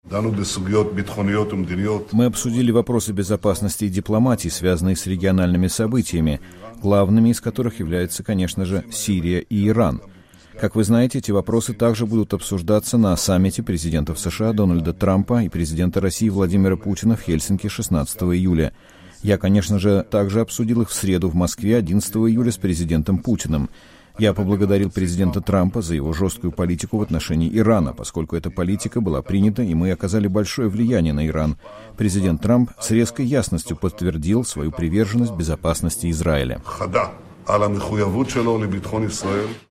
Премьер-министр Израиля Биньямин Нетаньяху в воскресенье во время заседания кабинета министров в Иерусалиме сообщил о телефонном разговоре 14 июля с президентом США Дональдом Трампом.